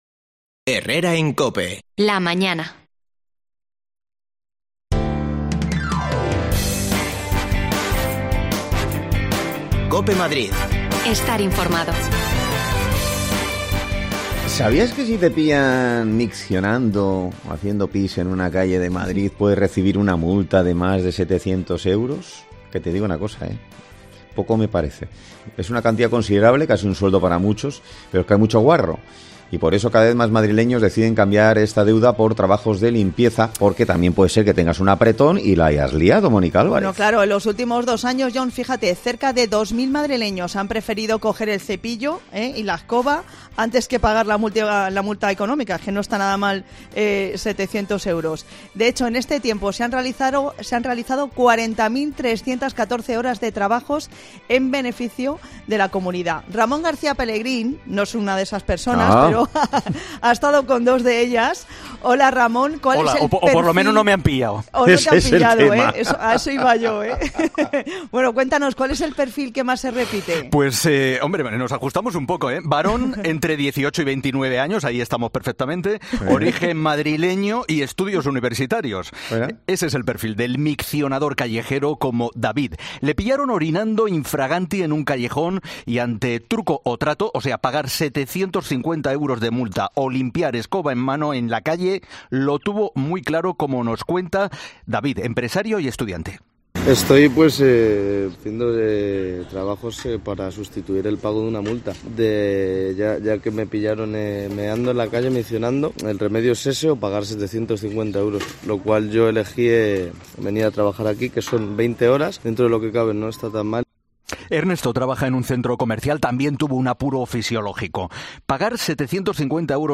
AUDIO: Escuchamos a dos jóvenes que han optado por realizar tareas de limpieza en vez de pagar la multa por hacer pipi en la calle...